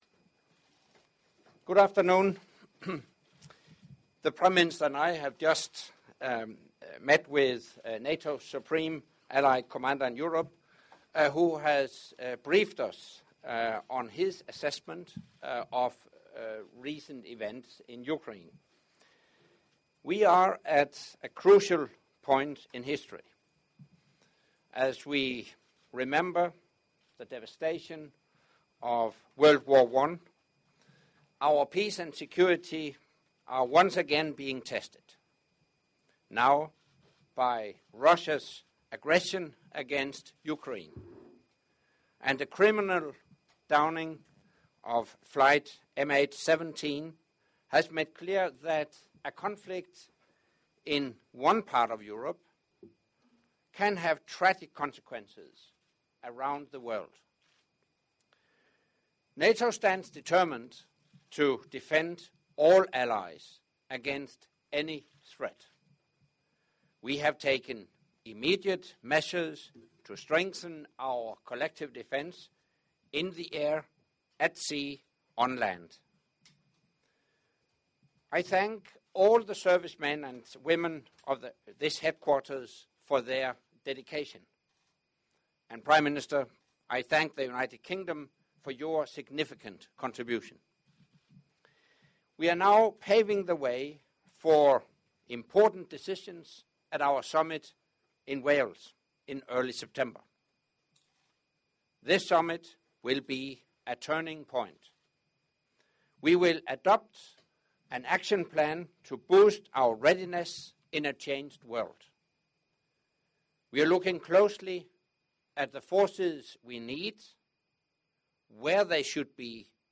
NATO Secretary General Anders Fogh Rasmussen discussed the impact of the Ukraine crisis on the Alliance’s collective security with British Prime Minister David Cameron and Supreme Allied Commander Europe (SACEUR) Gen. Philip Breedlove at Allied Command Operations headquarters in Mons, Belgium on Monday (4 August 2014). The Secretary General said that NATO “stands determined” to defend all Allies against any threat.